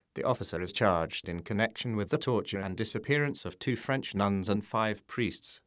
Here are some examples of the quality you can acheive with Festival 2.0